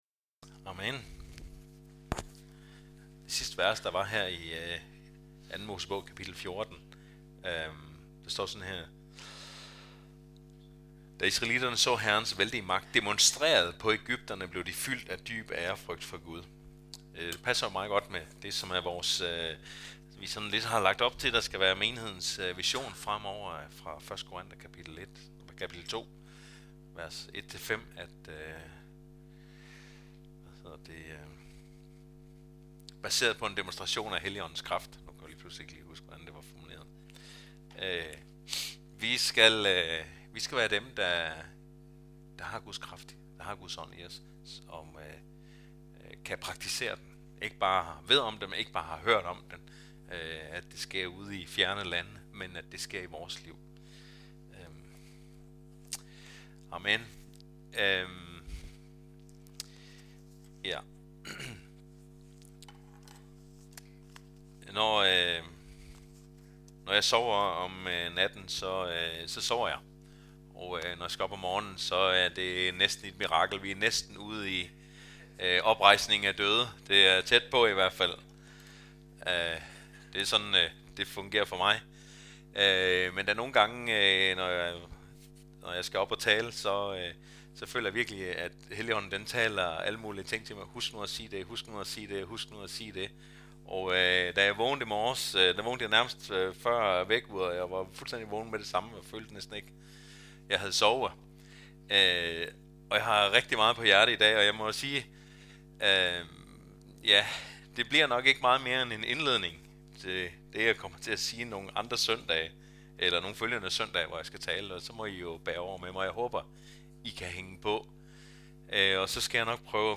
Gudstjeneste